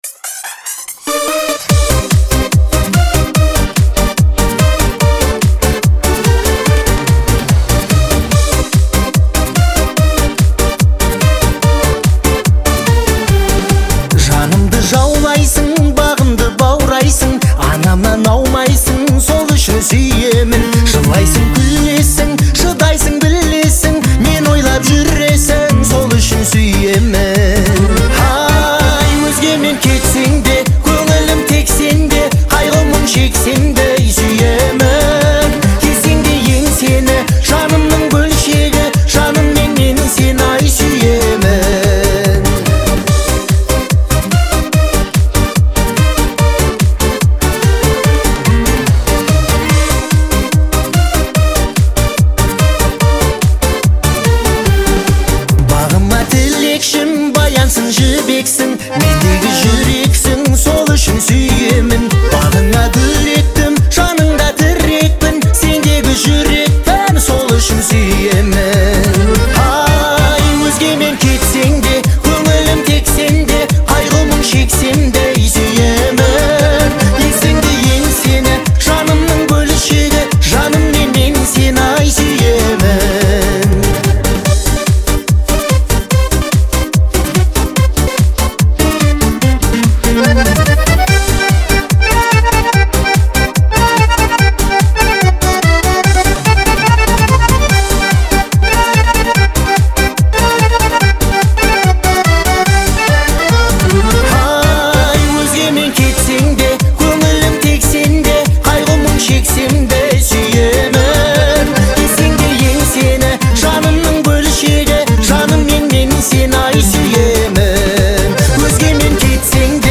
Особенностью исполнения является выразительный вокал